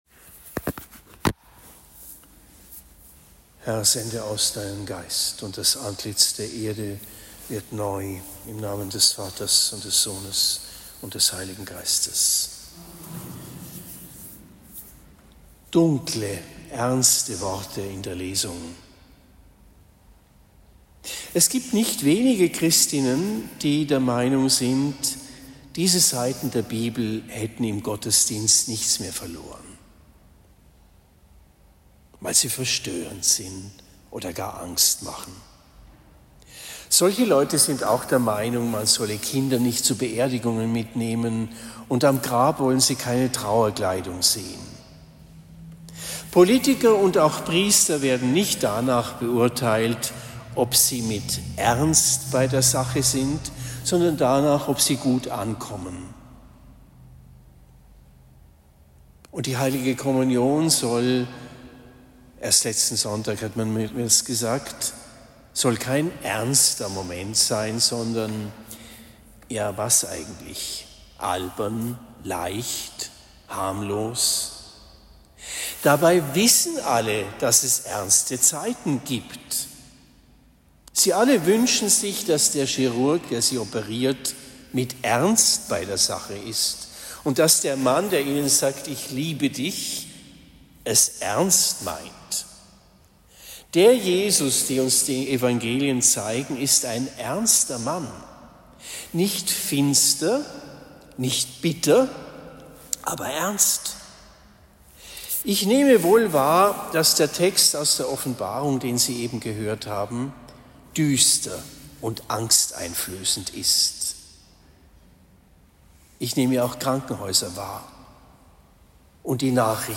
Predigt am 29. November 2024 in Oberndorf Herz-Mariä